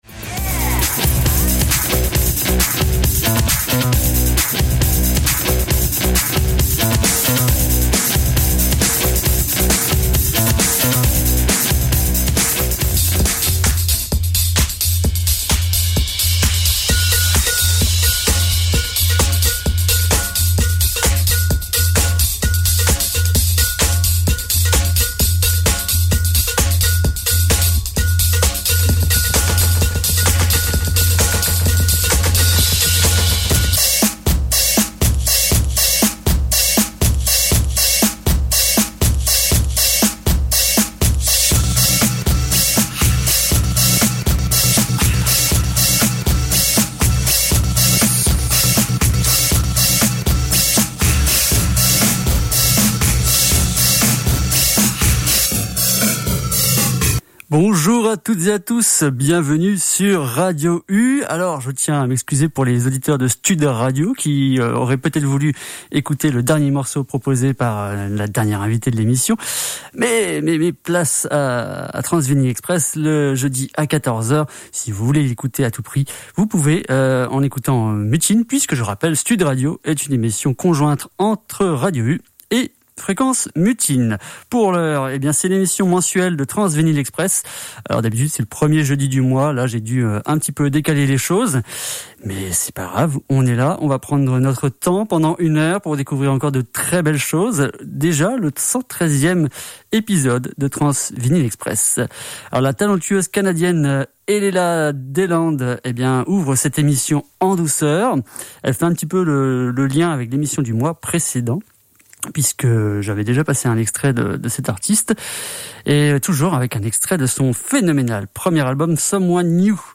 cuivres chaleureux
torride tube disco/funk
post-punk infusé à la house
cold-wave